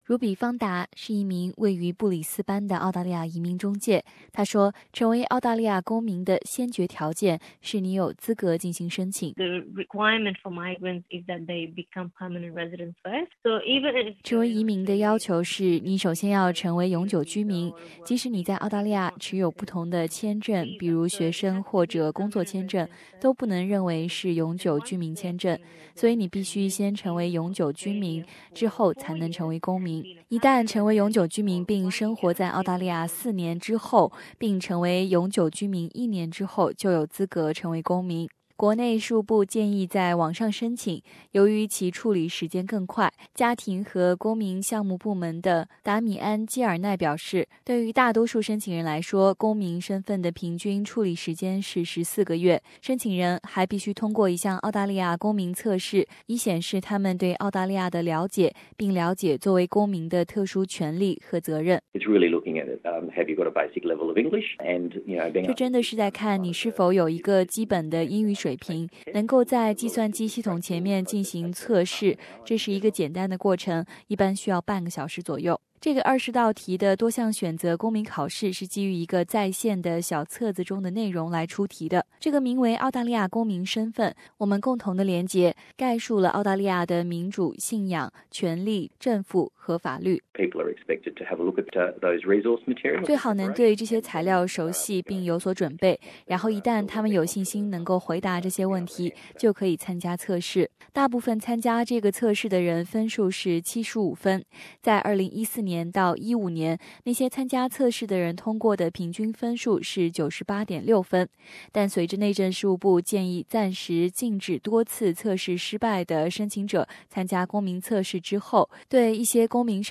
05:20 Source: AAP SBS 普通话电台 View Podcast Series Follow and Subscribe Apple Podcasts YouTube Spotify Download (2.44MB) Download the SBS Audio app Available on iOS and Android 你知道吗？